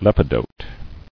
[lep·i·dote]